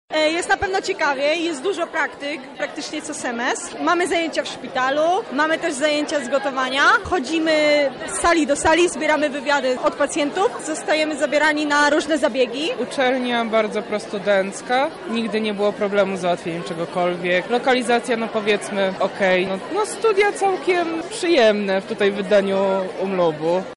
A co studiowaniu na Uniwersytecie Medycznym mówią jego studenci?
Dzień Otwarty na Uniwersytecie Medycznym